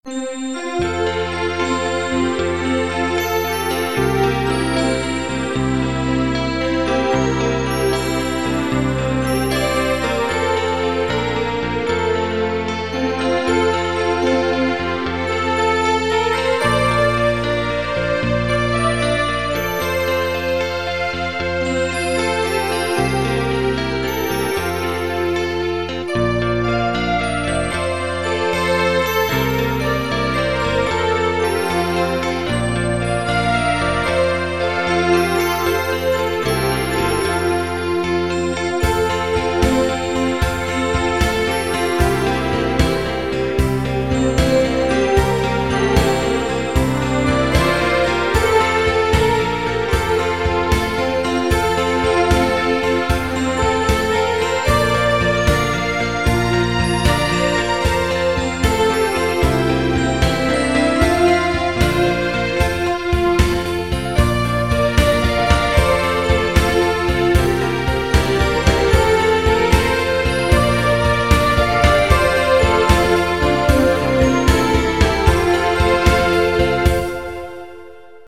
keyboard
happy-waltz-e-1.mp3